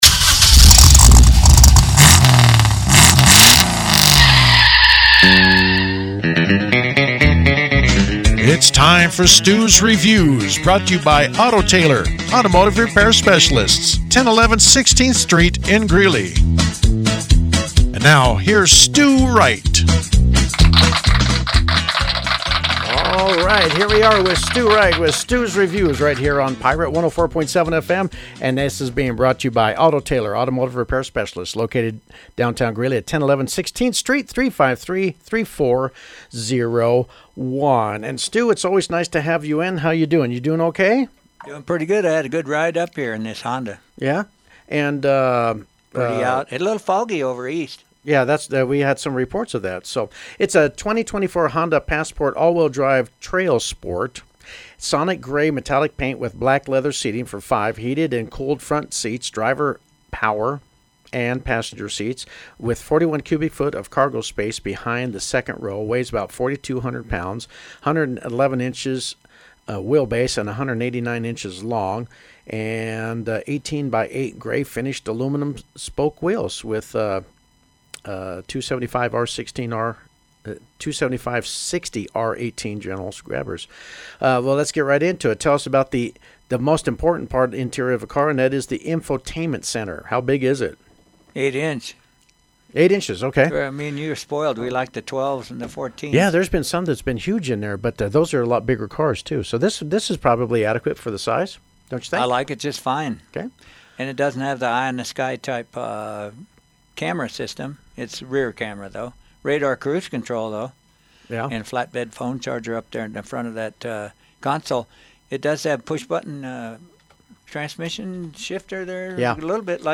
A review of the Honda Passport was broadcast on Pirate Radio 104.7FM in Greeley: